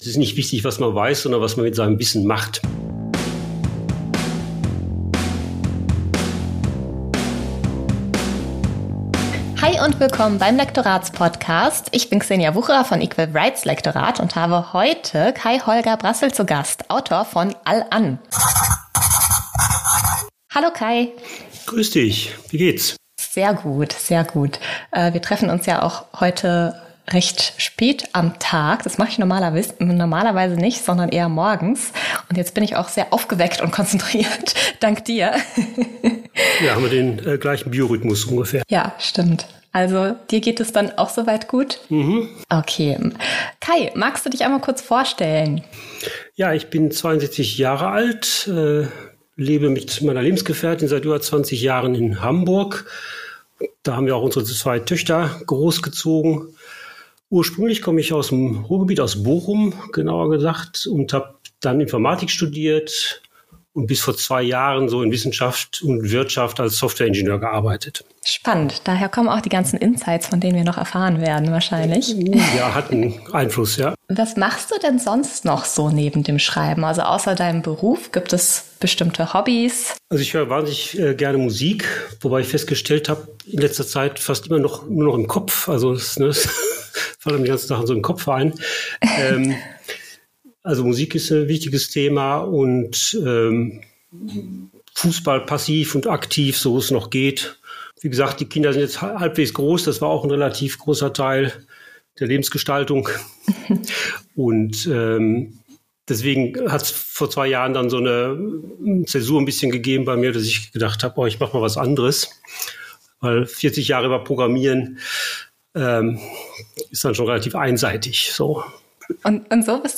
#12 Lektorin interviewt Autor